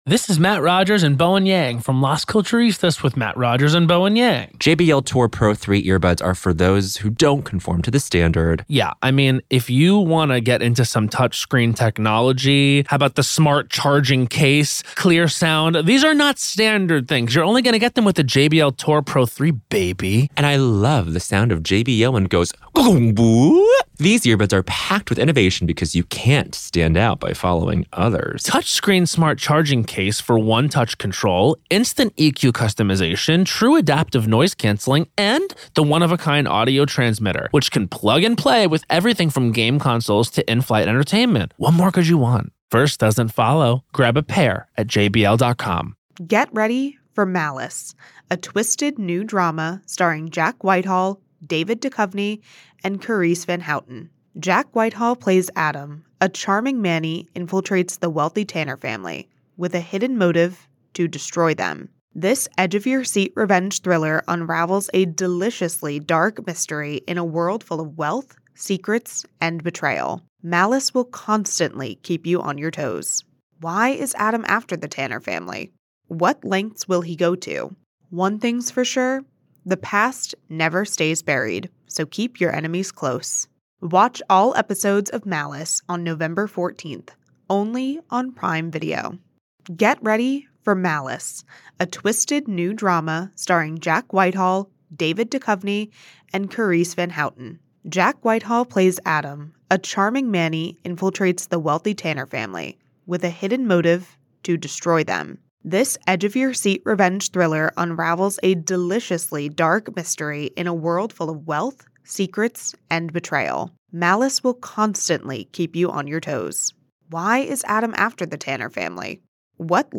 Each episode navigates through multiple stories, illuminating their details with factual reporting, expert commentary, and engaging conversation.
Expect thoughtful analysis, informed opinions, and thought-provoking discussions beyond the 24-hour news cycle.